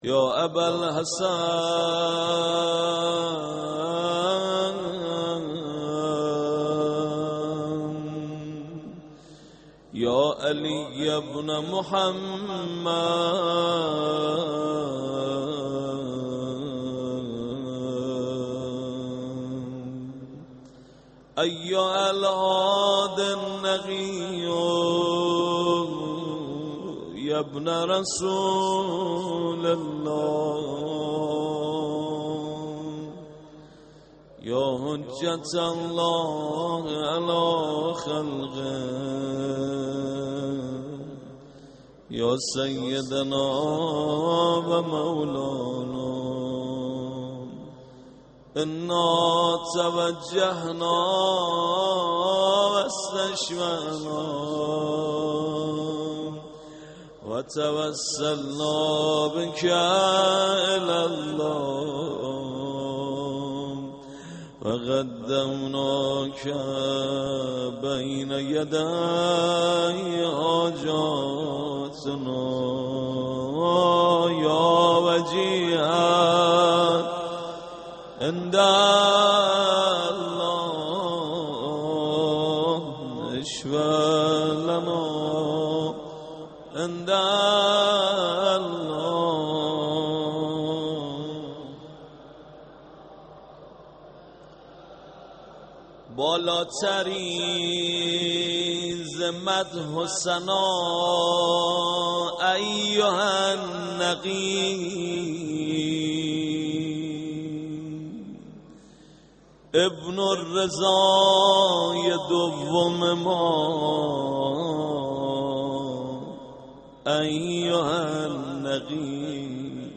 مرثیه